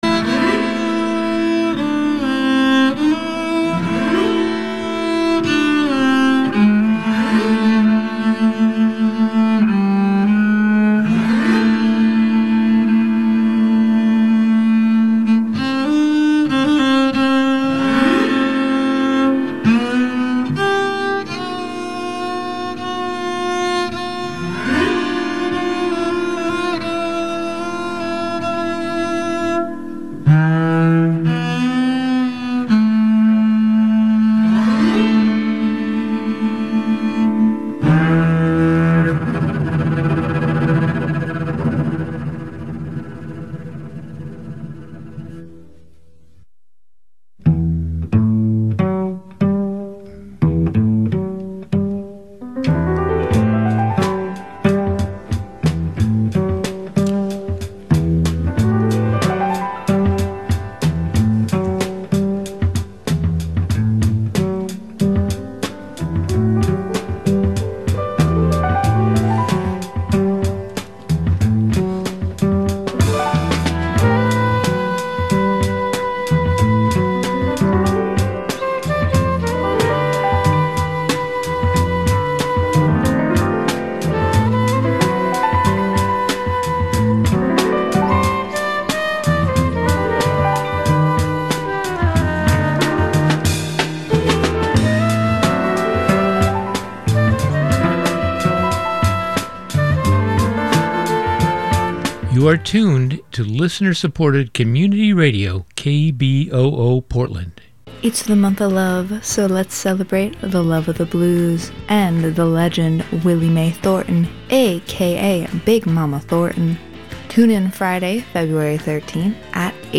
INVESTIGATIVE News Radio